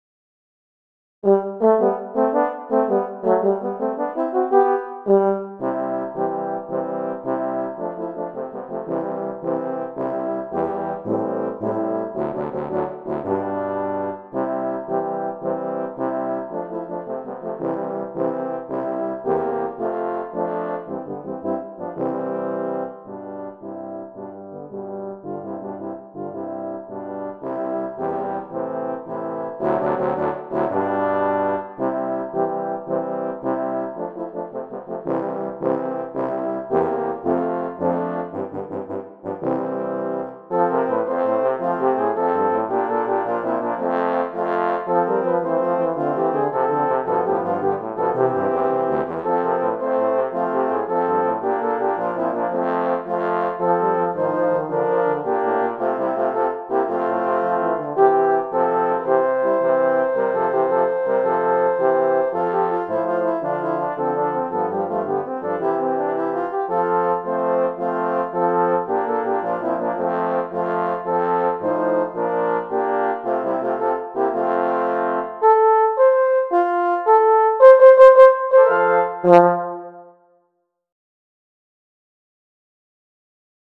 I have arranged it for horn quartet using the FINALE software.